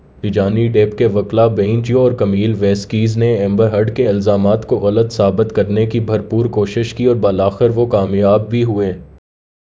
deepfake_detection_dataset_urdu / Spoofed_TTS /Speaker_11 /262.wav